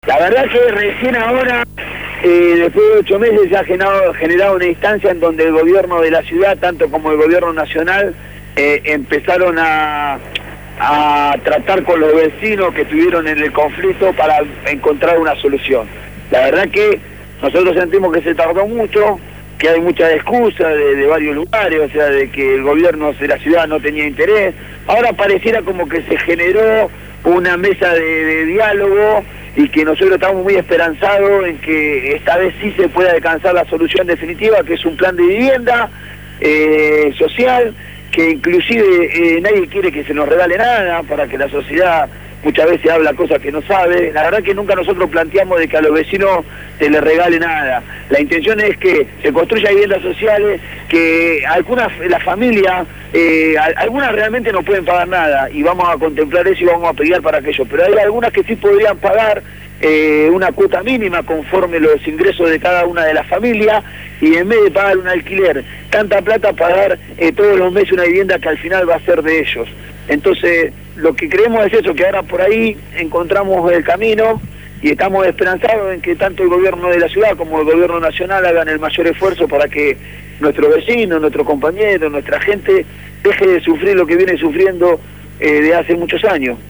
En conversación telefónica